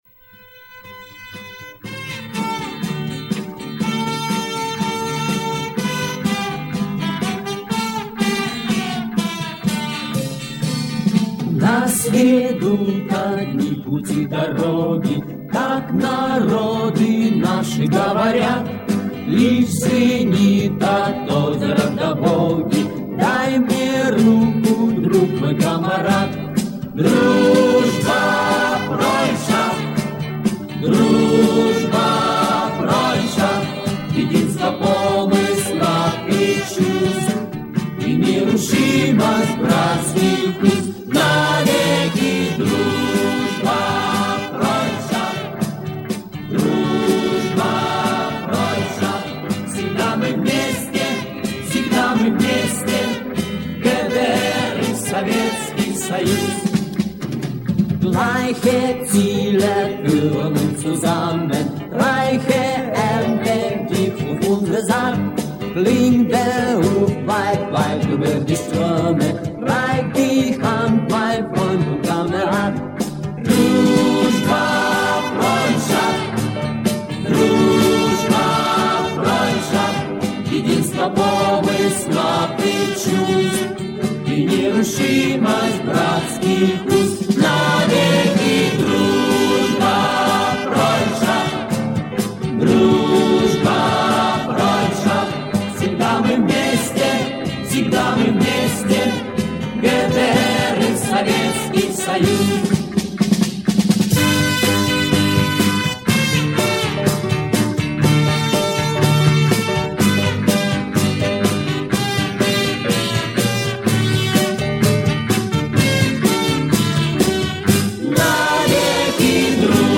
У него было такое прекрасное немецкое произношение!
Песня была и на русском и на немецком.